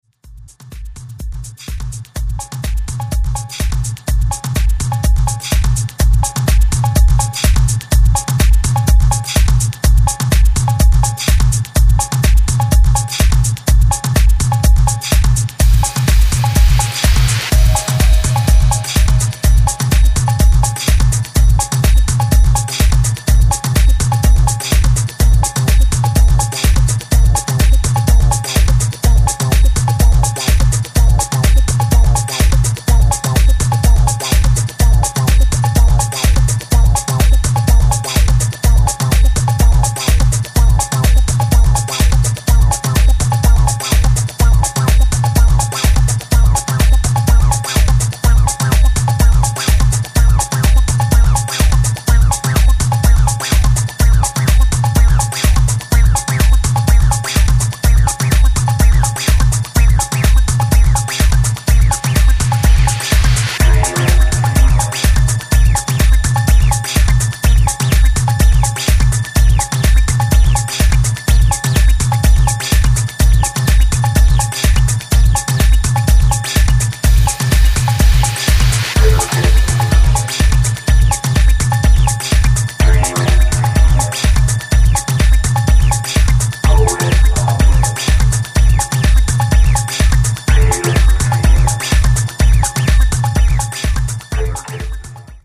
The B1 track is quite funky...
Electro House Acid